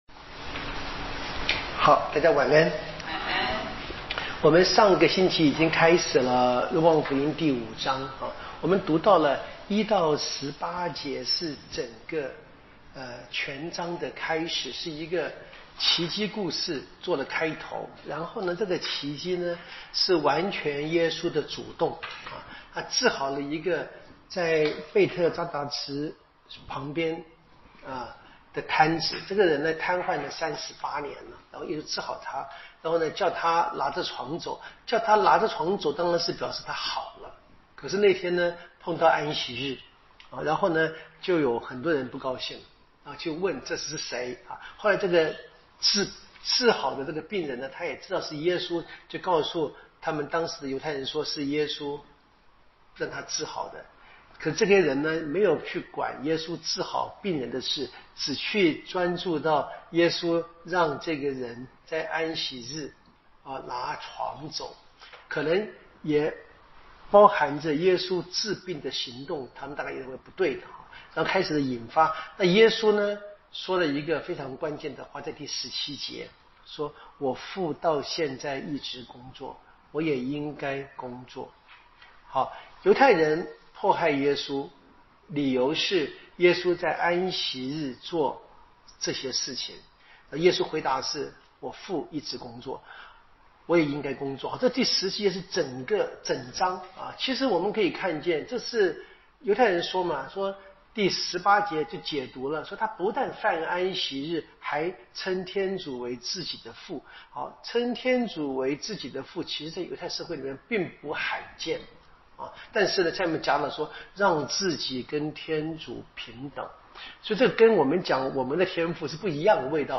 【圣经讲座】《若望福音》